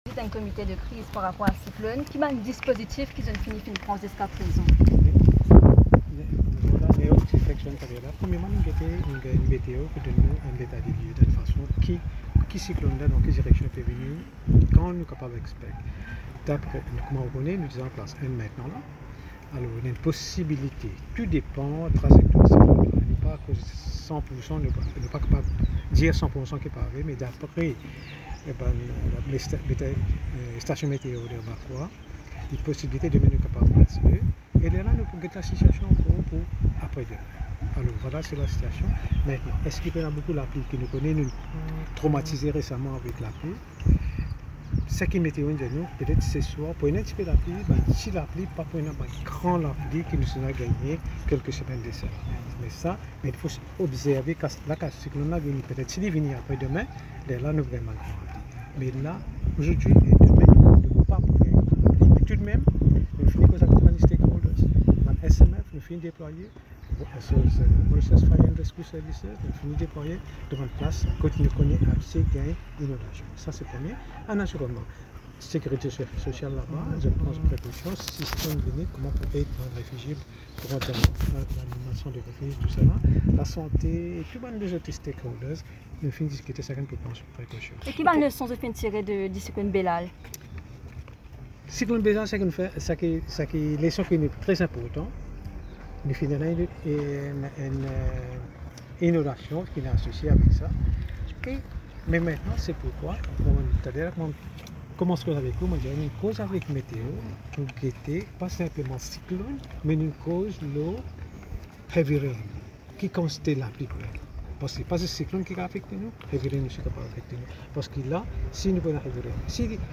Il intervenait lors d’une visite de site de logement de la National Social Living Development Ltd t (NSLD), à Riche-Terre, ce mardi 20 février.